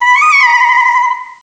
pokeemerald / sound / direct_sound_samples / cries / meloetta.aif